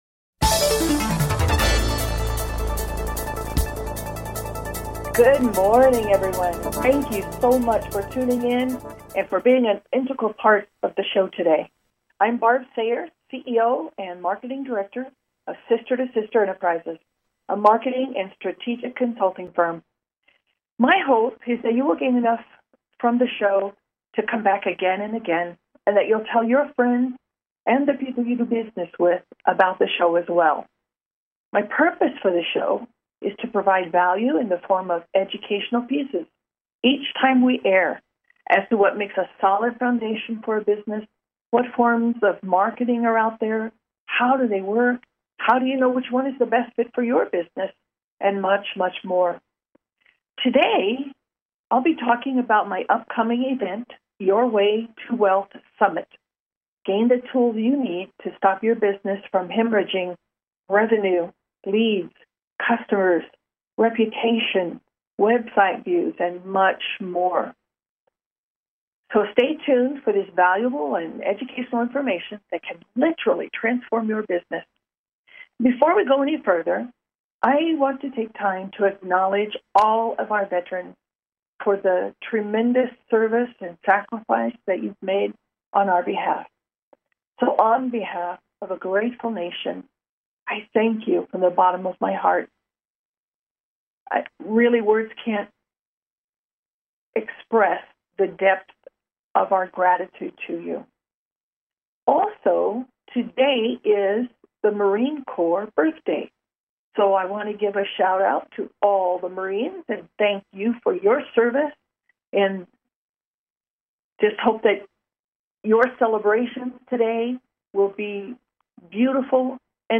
Talk Show Episode
Call-ins encouraged!